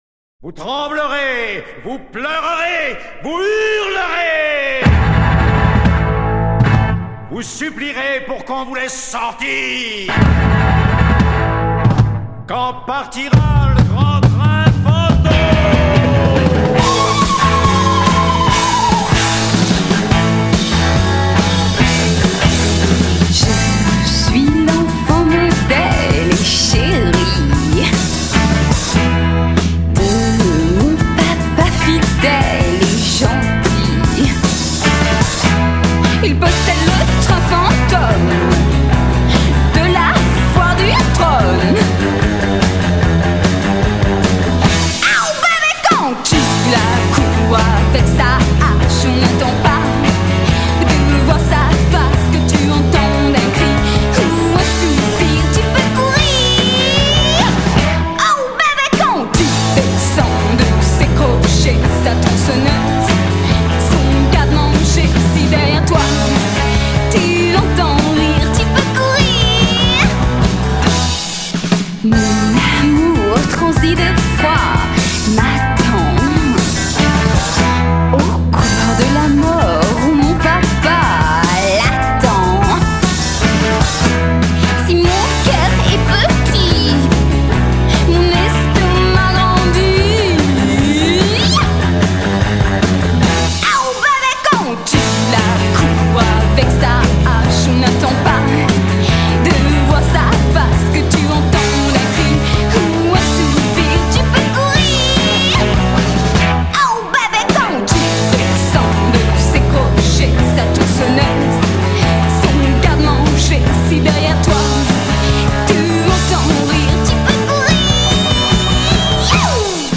chansons pop-rock avec des paroles en français